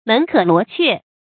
注音：ㄇㄣˊ ㄎㄜˇ ㄌㄨㄛˊ ㄑㄩㄝˋ
門可羅雀的讀法